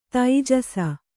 ♪ taijasa